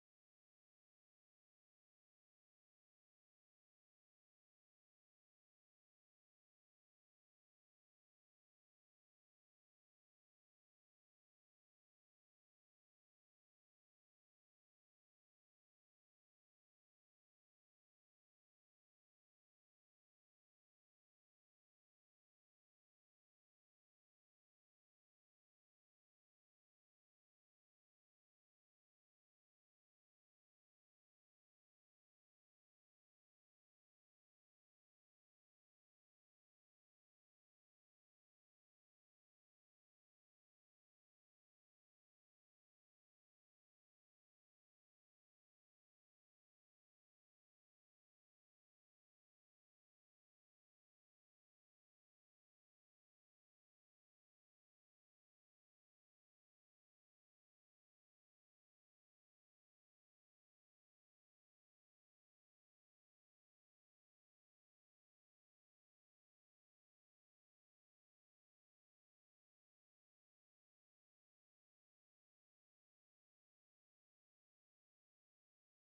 New Warning Light Bar Plus sound effects free download